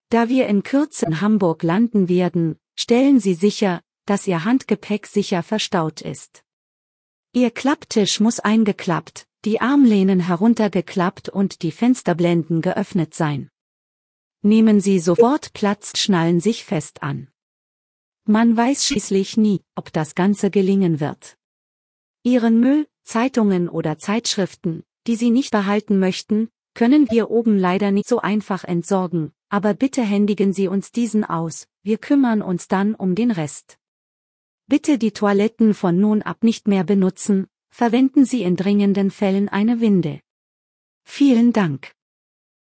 DescentSeatbelts.ogg